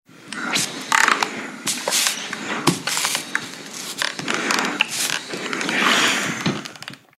Planchar ropa mojada
Sonidos: Acciones humanas
Sonidos: Hogar